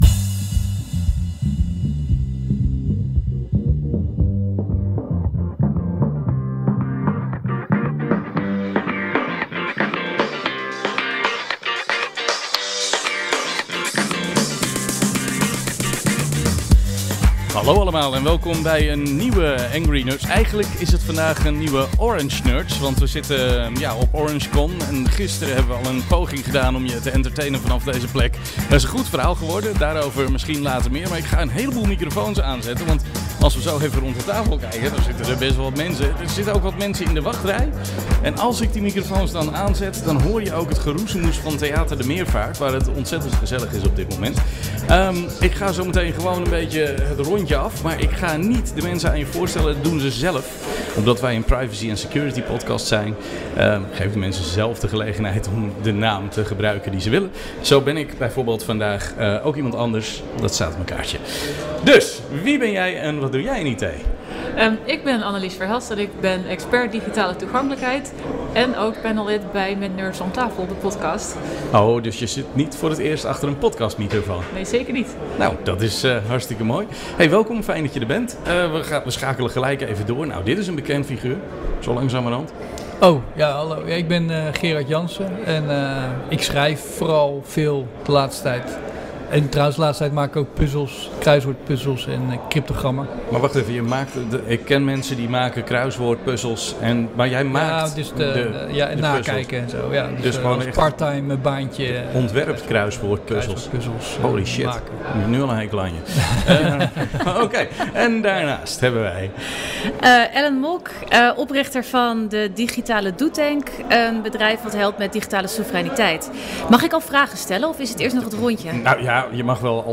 Angry Nerds - Live op OrangeCon 2025.mp3